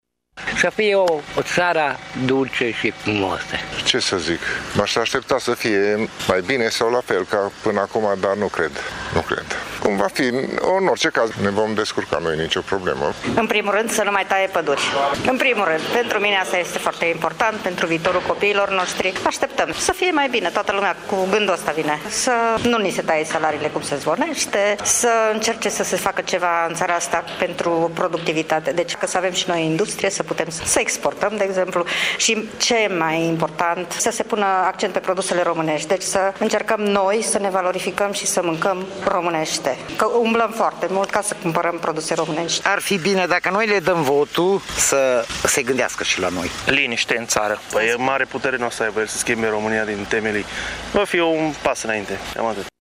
Totuși, unii târgumureșeni speră în continuare la o Românie ”dulce și frumoasă”: